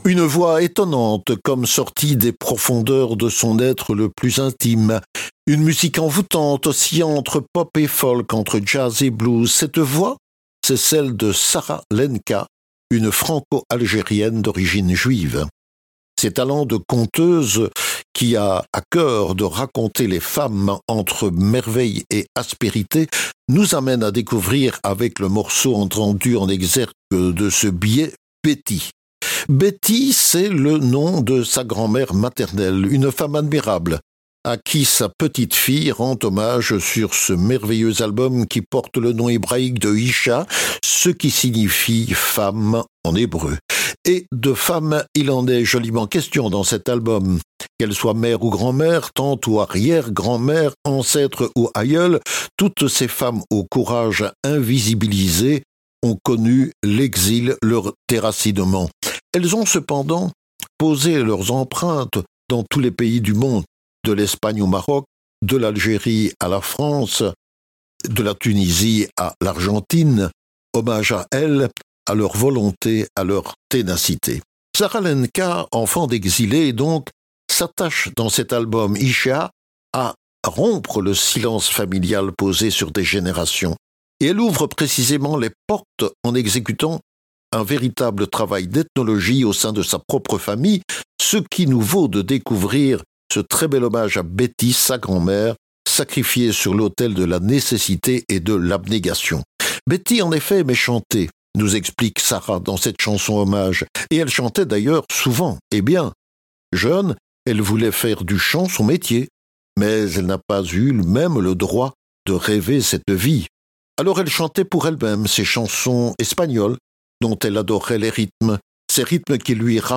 un chant arabo-andalou envoûtant